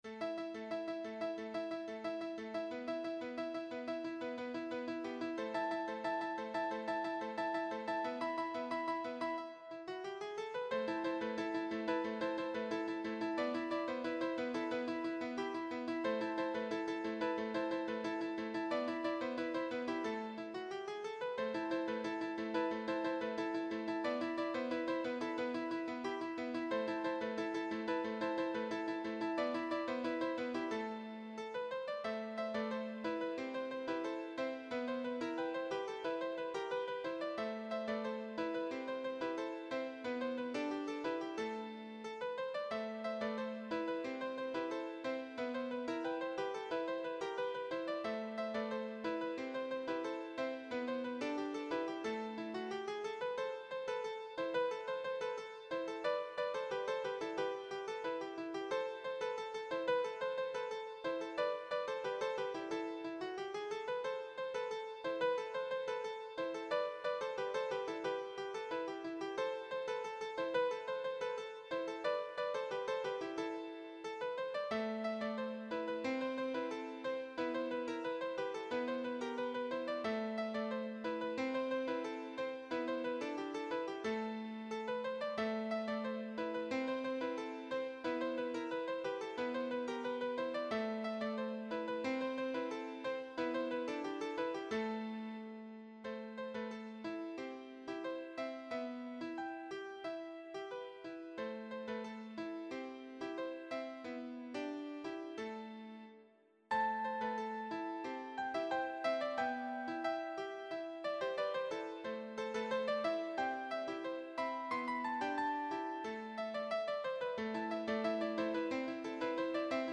Schottisch
Tonumfang A-C3, A-moll
midi Tonbeispiel Klavier